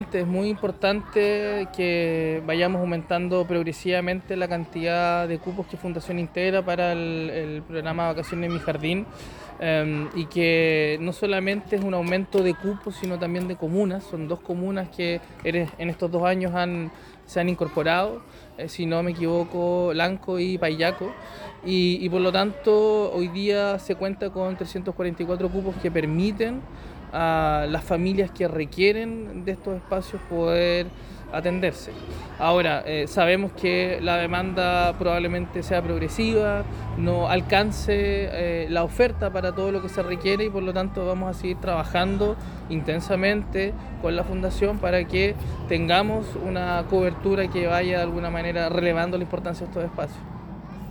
Juan-Pablo-Gerter-Seremi-de-Educacion.mp3